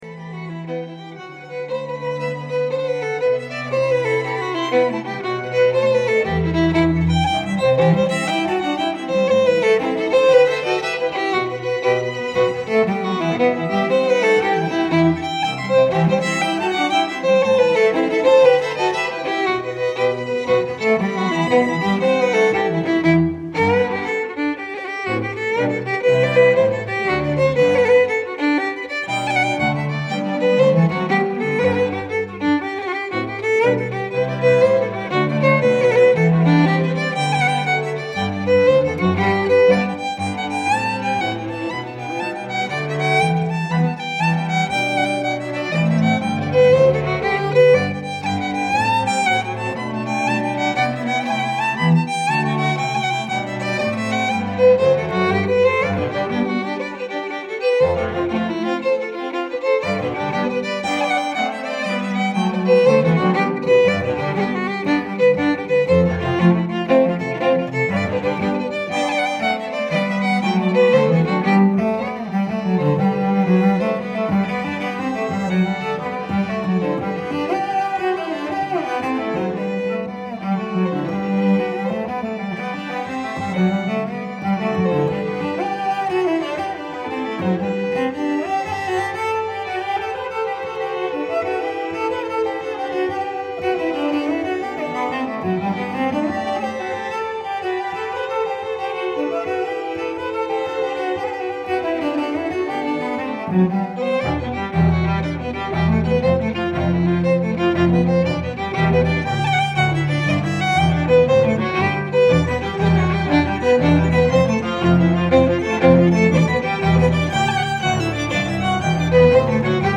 Tracks with string quartet
1st Movement (mid-point of the jig set , 2 min.)